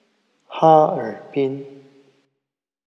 Harbin_pronunciation.ogg.mp3